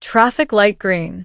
traffic_sign_green.wav